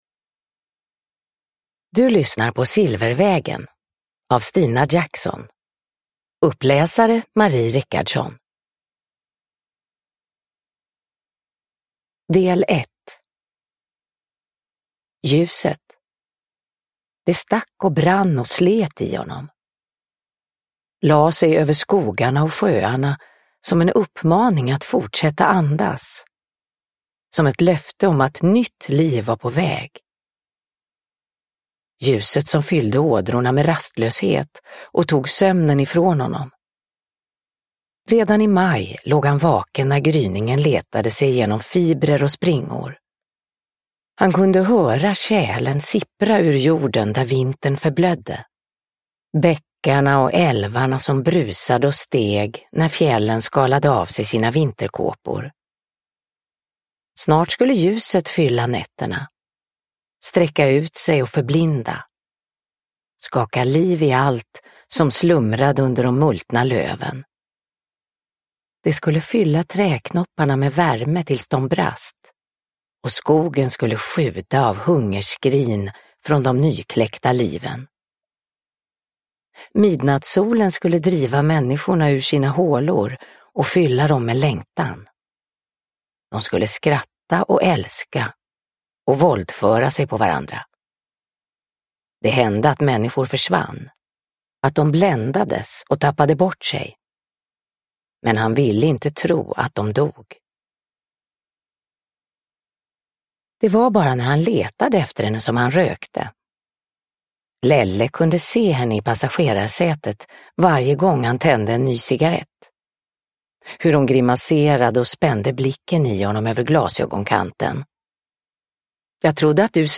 Silvervägen – Ljudbok
Uppläsare: Marie Richardson